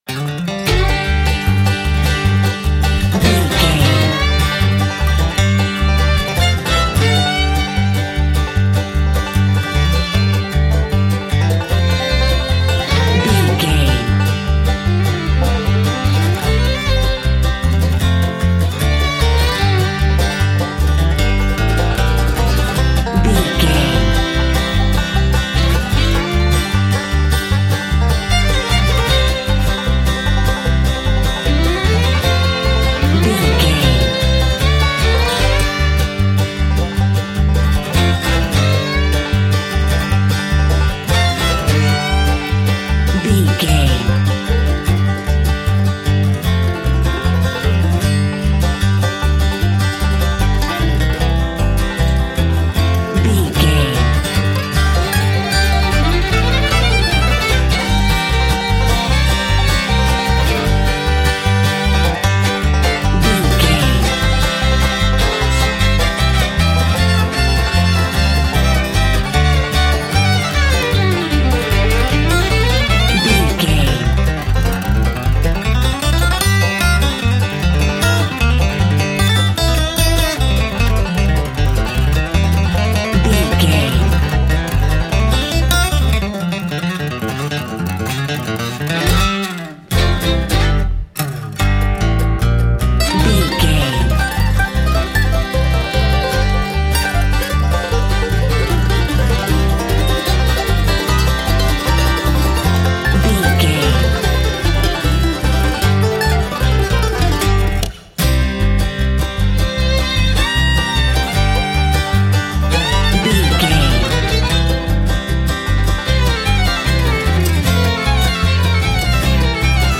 Ionian/Major
banjo
violin
double bass
acoustic guitar